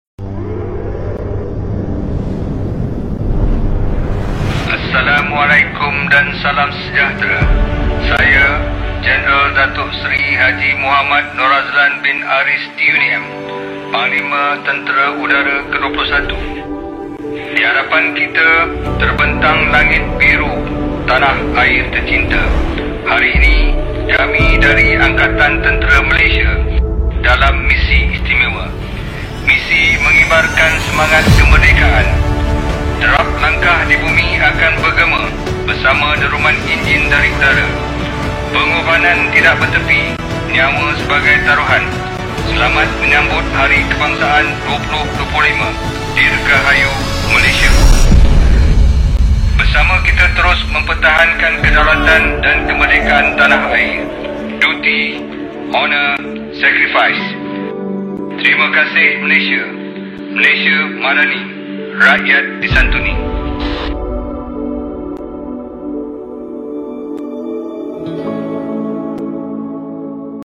Amanat Panglima Tentera Udara Jeneral Dato Seri Norazlan Aris Giro Dalam Ucapan Khas Kemerdekaan ke 68